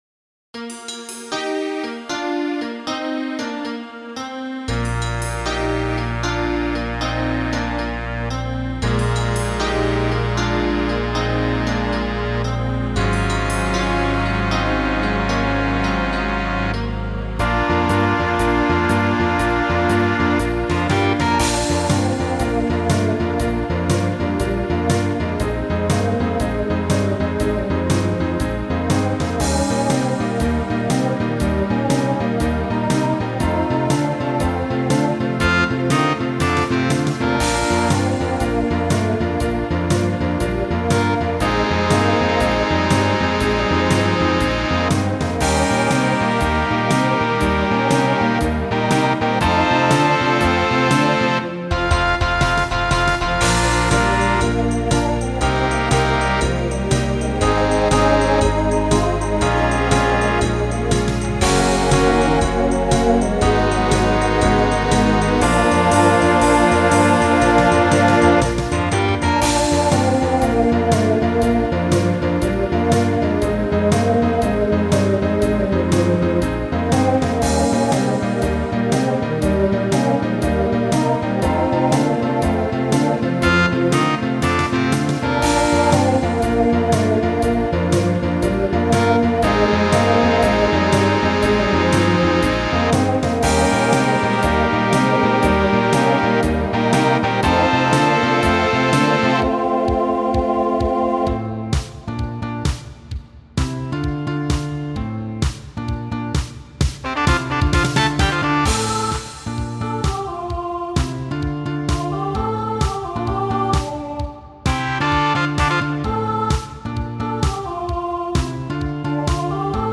TTB/SSA
Voicing Mixed Instrumental combo Genre Pop/Dance , Rock